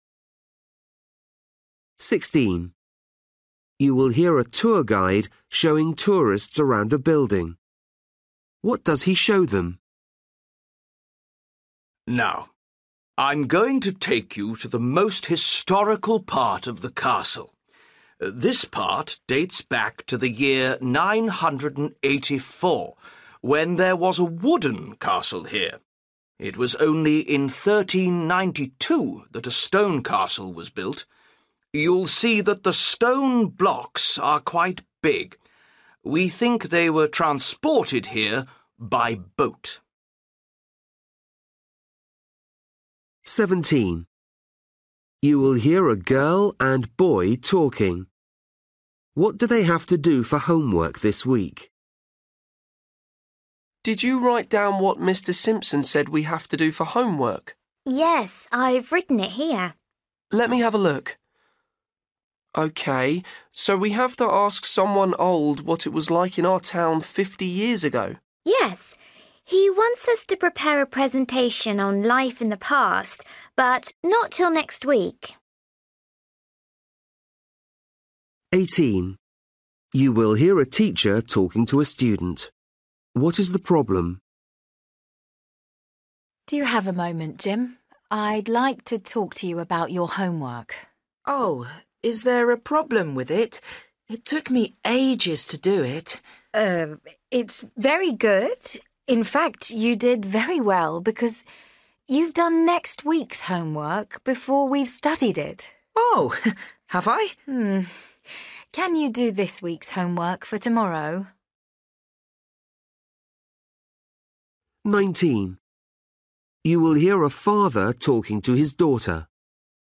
Listening: everyday short conversations
16   You will hear a tour guide showing tourists around a building.
17   You will hear a girl and boy talking.
19   You will hear a father talking to his daughter.
20   You will hear a woman in a library talking to a boy.